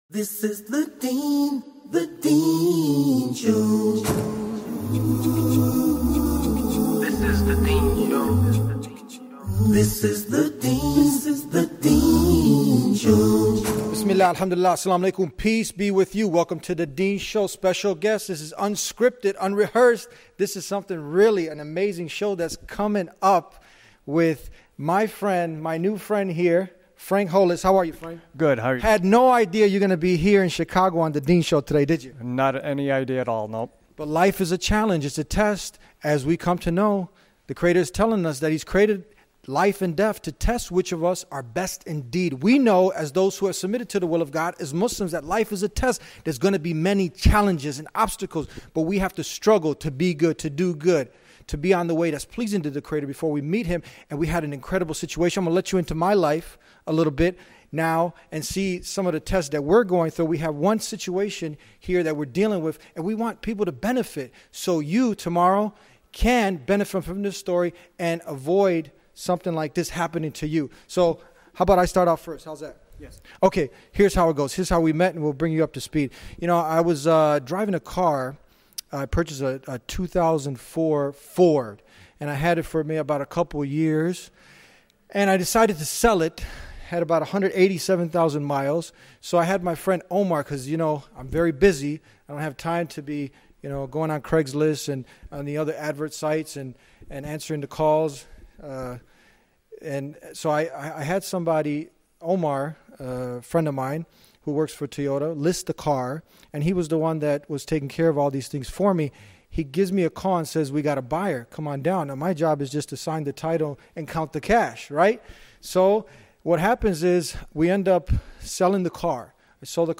The victim of a Used car Scam comes on TheDeenShow to tell his story of what happened to him and how you can avoid the same thing happening to you.